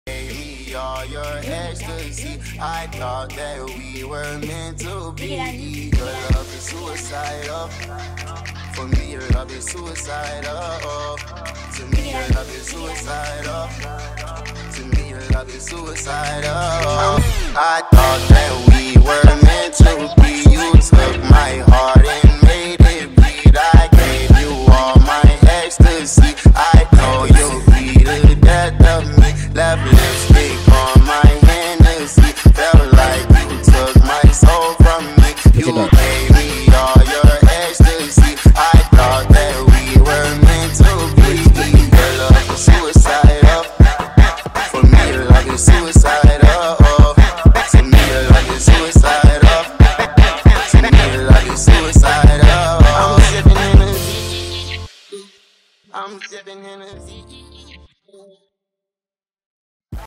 Jersey Club